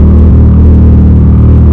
rumble_rf1V8_in.wav